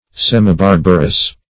Semibarbarous \Sem`i*bar"ba*rous\, a. Half barbarous.